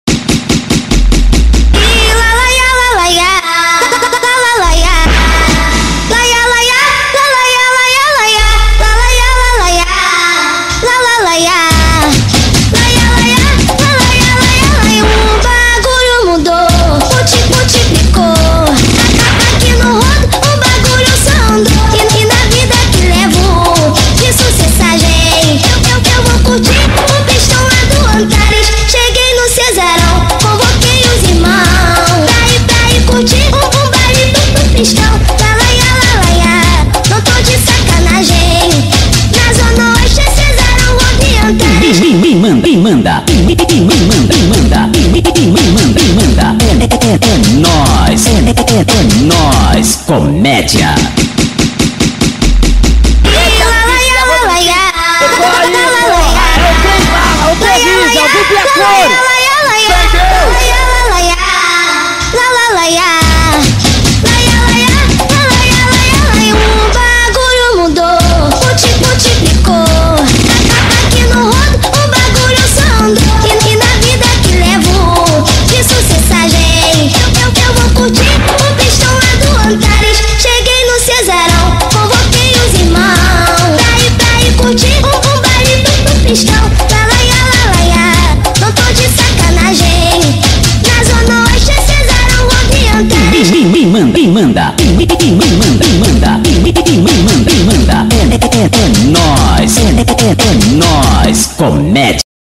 2024-08-08 17:15:20 Gênero: Arrocha Views